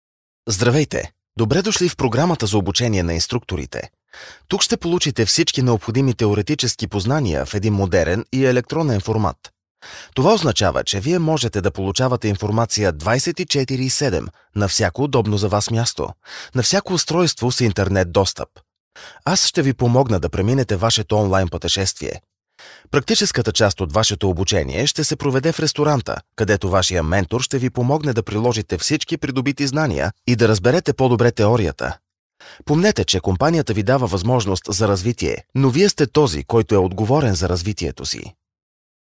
Commercieel, Natuurlijk, Stoer, Warm, Zakelijk
E-learning